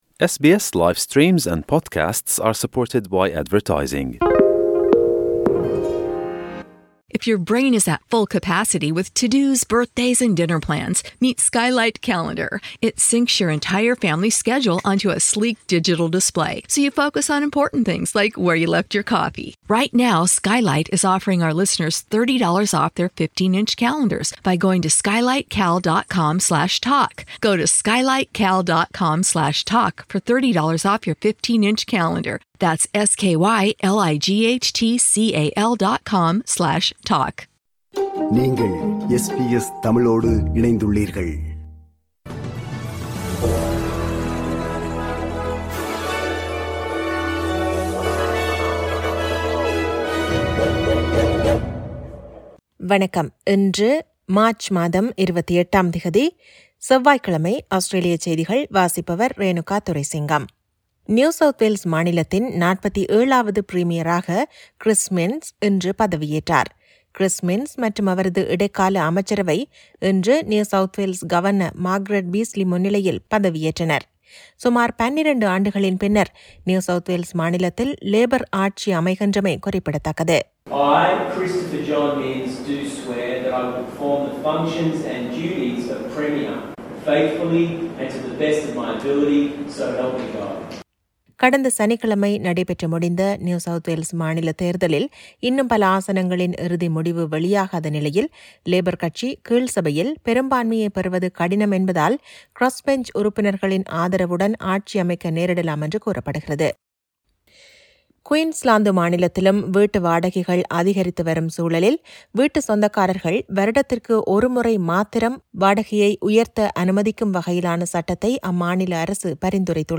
Australian news bulletin for Tuesday 28 Mar 2023.